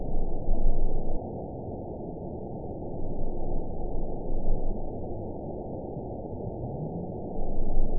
event 920063 date 02/21/24 time 00:38:26 GMT (2 months, 1 week ago) score 9.18 location TSS-AB06 detected by nrw target species NRW annotations +NRW Spectrogram: Frequency (kHz) vs. Time (s) audio not available .wav